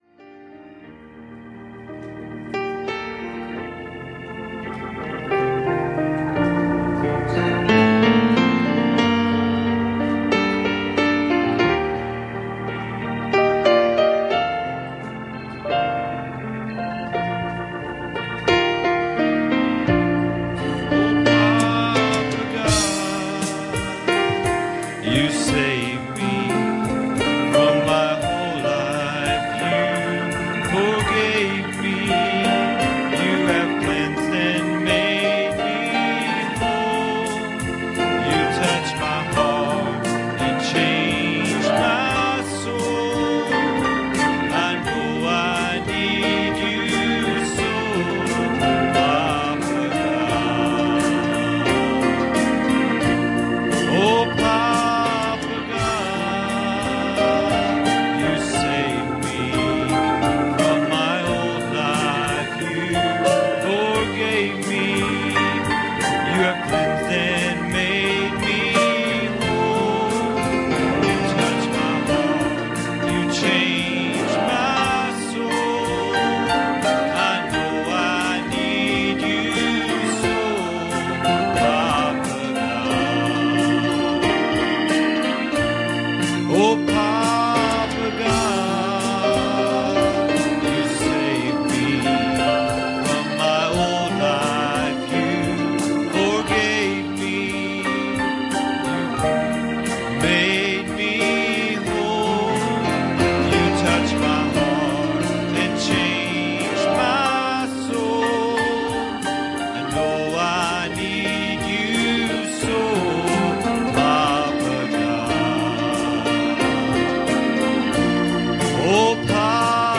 Series: Sunday Evening Services
Service Type: Sunday Evening